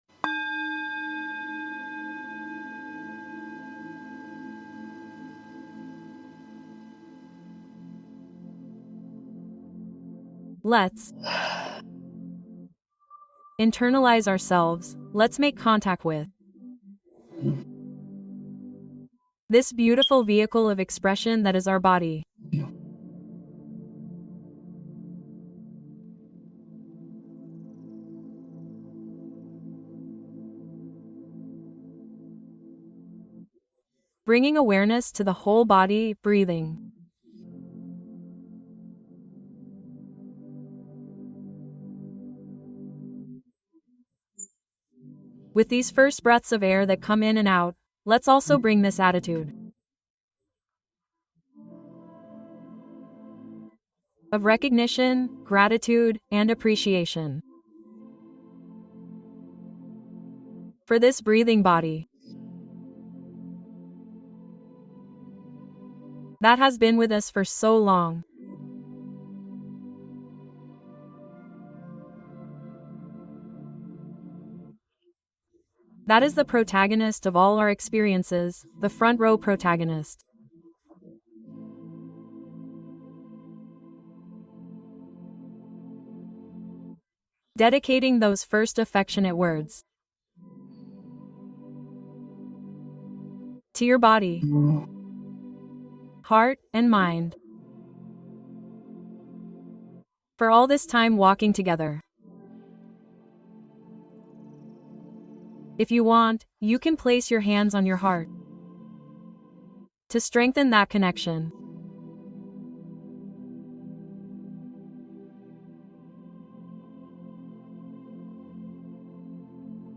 Meditación guiada de la sonrisa interior: sanación y bienestar profundo